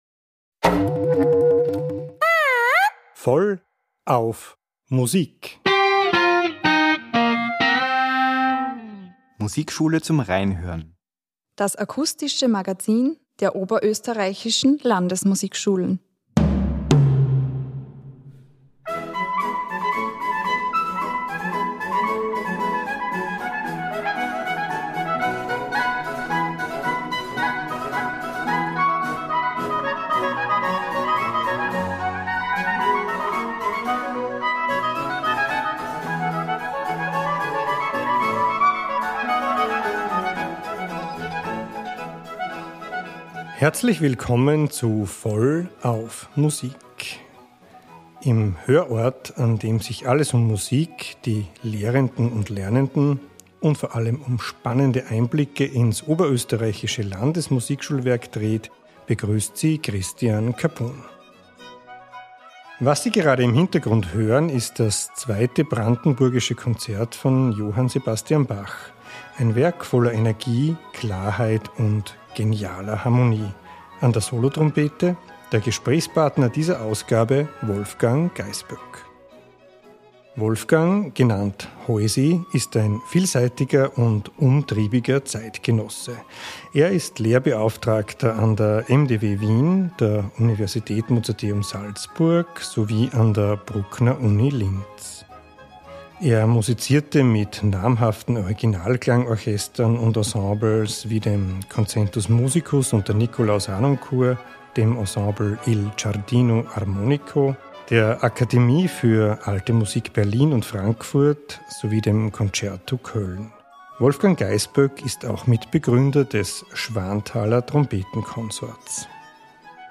In der aktuellen Episode plaudern wir mit